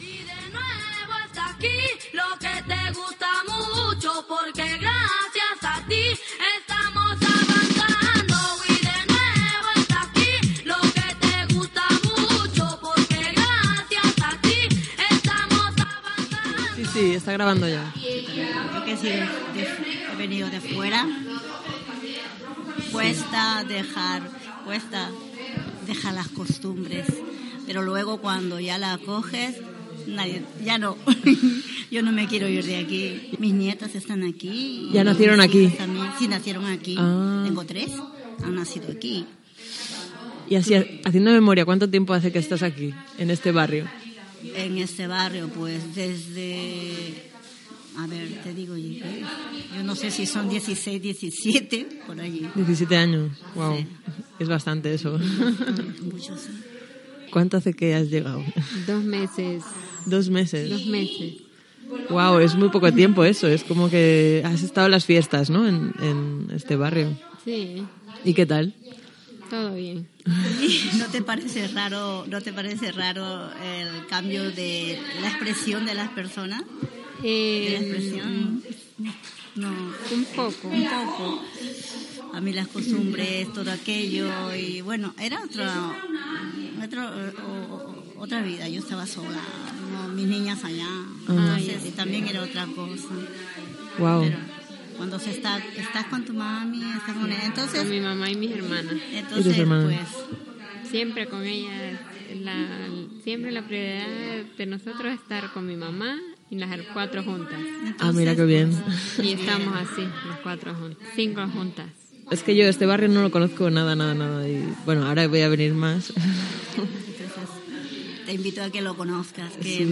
Explicacions de tres veïnes de La Florida. Una, d'origen peruà, hi vivia des de feia 17 anys, una altra que hi residia des de feia dos mesos i una tercera amb família procendent de Ciudad Real